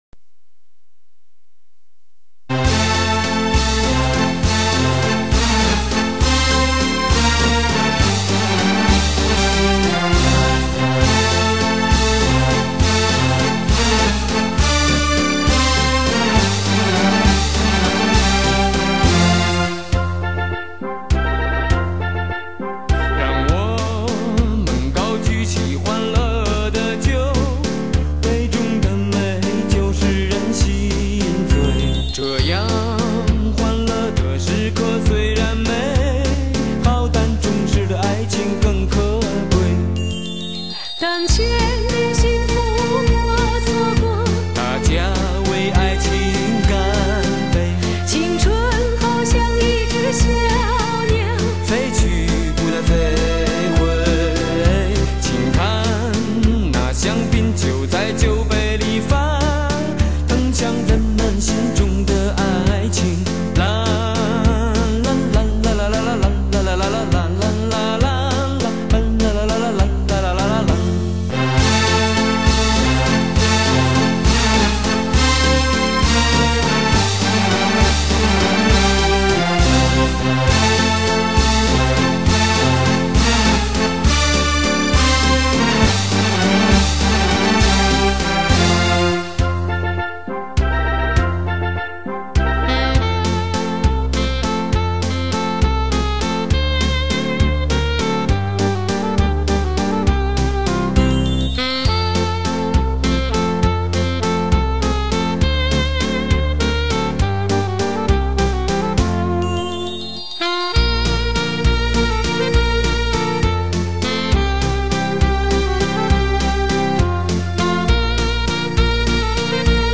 (快三)